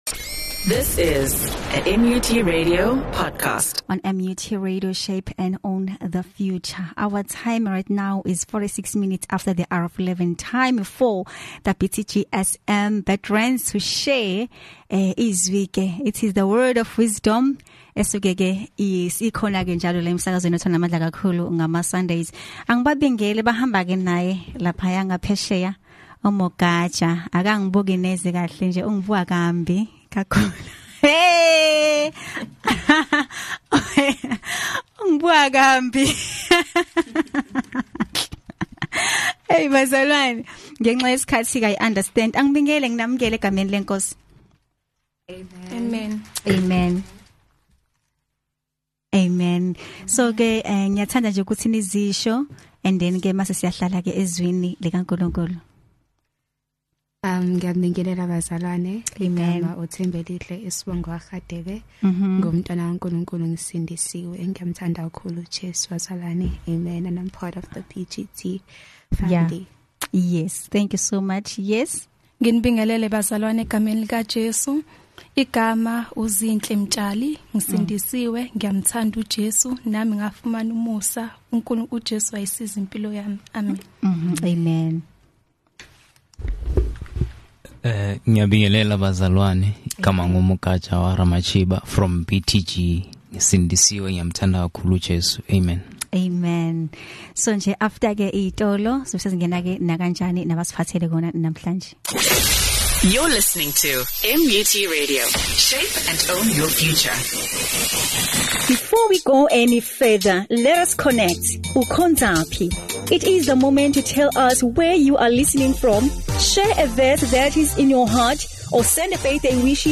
Interfaith